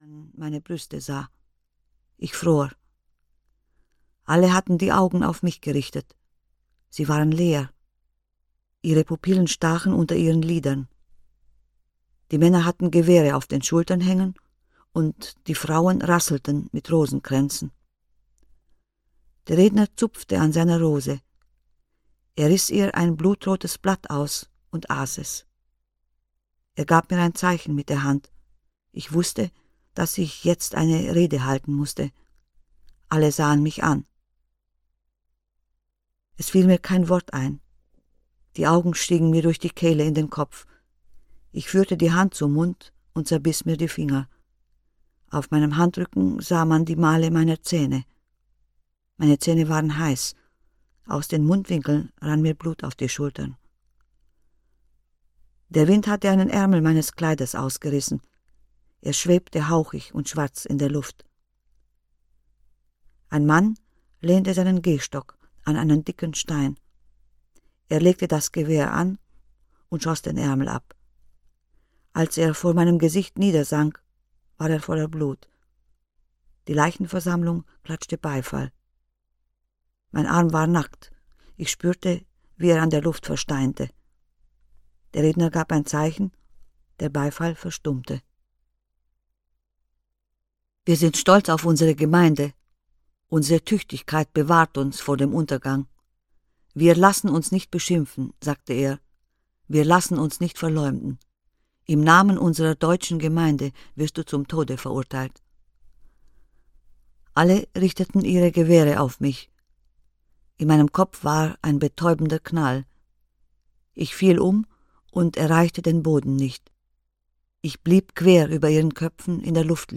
Niederungen - Herta Müller - Hörbuch